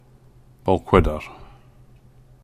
^ Pronounced /bælˈhwɪdər/ or /bælˈkwɪdər/